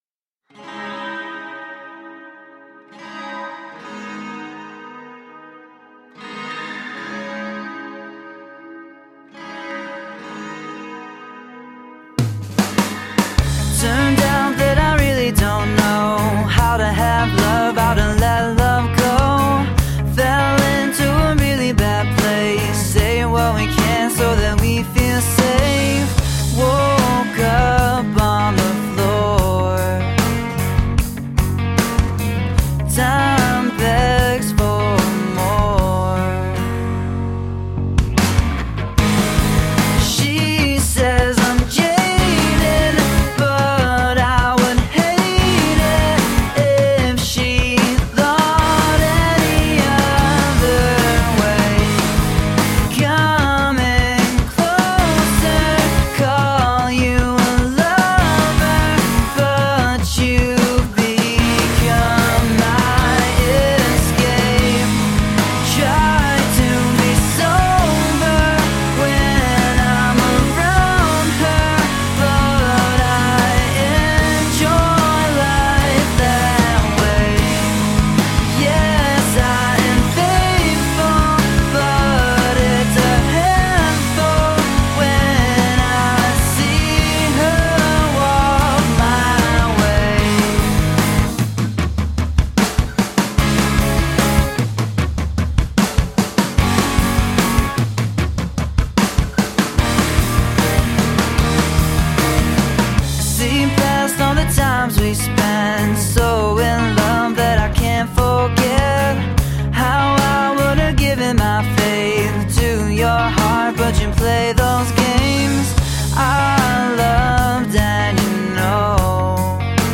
Guitar driven alternative rock.
Tagged as: Alt Rock, Rock, Folk-Rock, Indie Rock